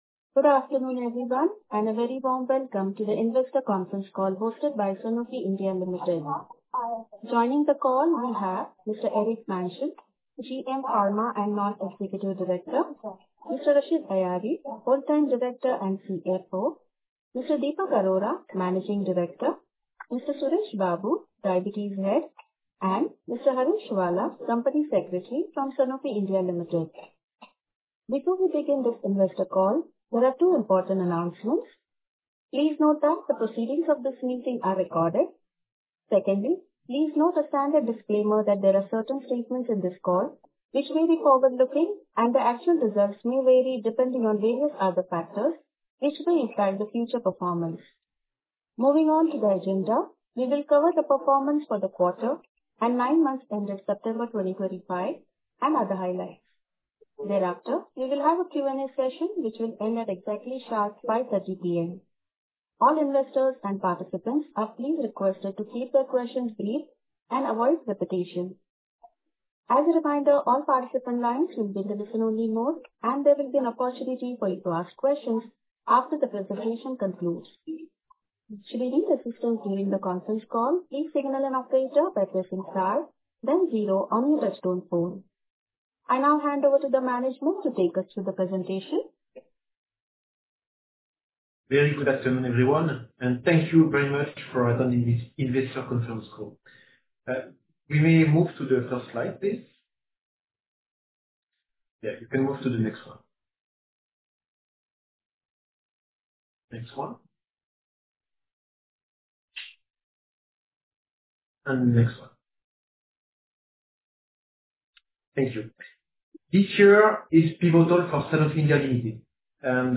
Sanofi India Limited Investor Conference Call on 29-Oct-2025